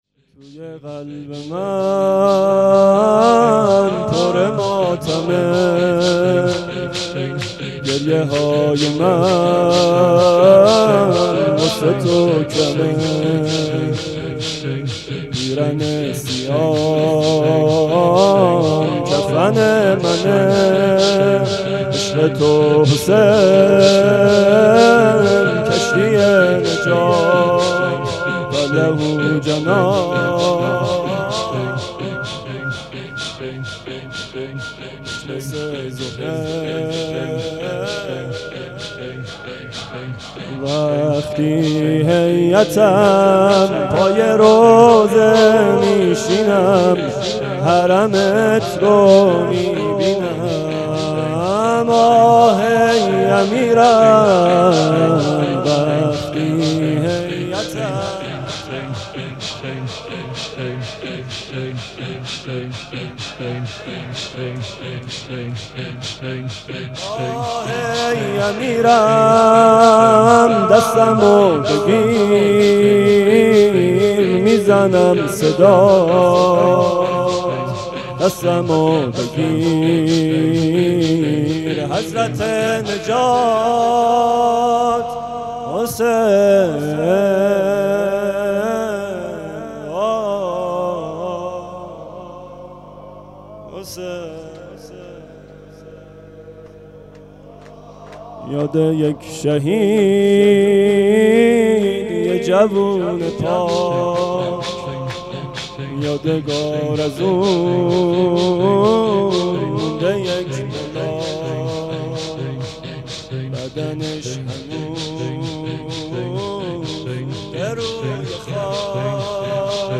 محرم 97
دم پایانی